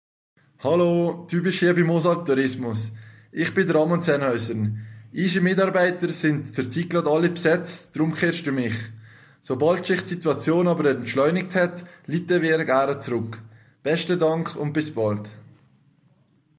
Seit kurzem hören sie nämlich die Stimme von Skirennfahrer Ramon Zenhäusern, wenn sie ausserhalb der Öffnungszeiten anrufen oder gerade alle Mitarbeiter des Info Centers besetzt sind.
Besetzt.mp3